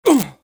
Grunts Male
Grunts Male.wav